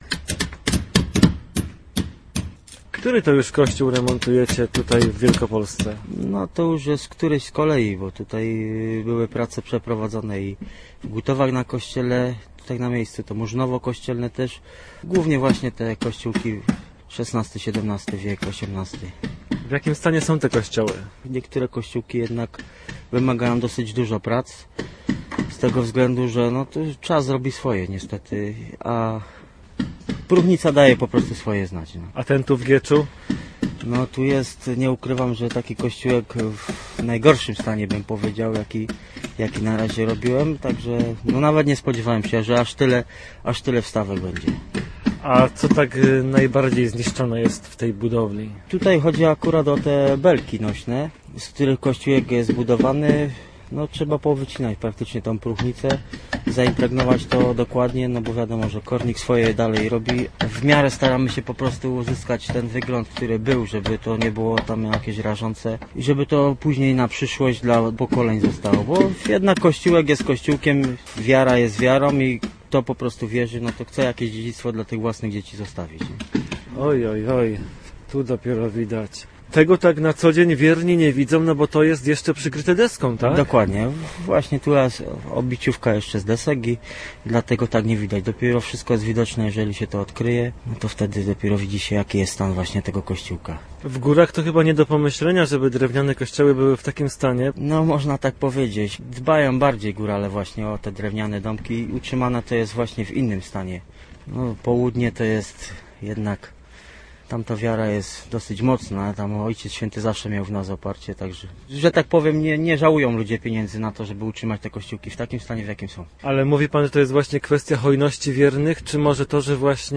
Górale z Witowa koło Zakopanego remontują zabytkowy drewniany kościół na wczesnopiastowskim grodzisku w Gieczu. Nasz reporter odwiedził górali i "pogodoł"